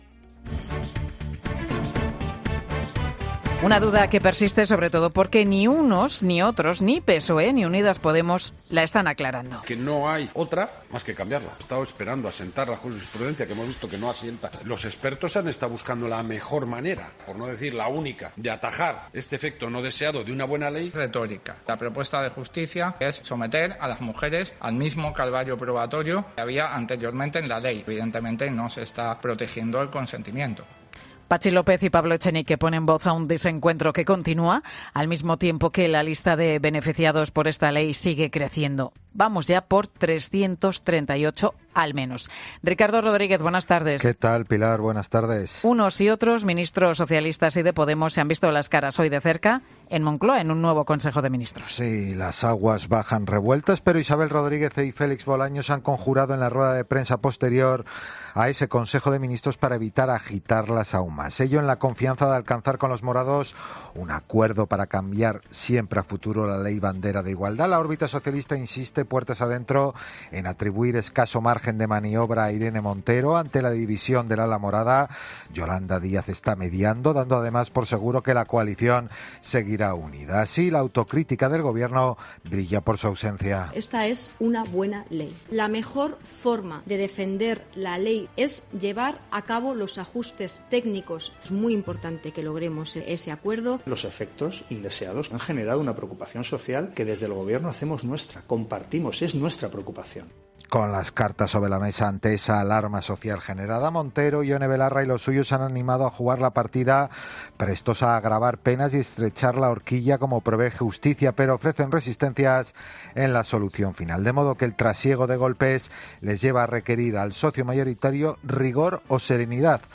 Por su parte, la ministra portavoz del Gobierno, Isabel Rodríguez, ha subrayado en la rueda de prensa que la "mejor forma" defender la "buena ley" es "llevar a cabo todos los ajustes técnicos necesarios" para evitar la rebaja de penas y ha insistido en que no se ha planteado tocar "ni una coma" del consentimiento" porque es la "esencia" de la norma.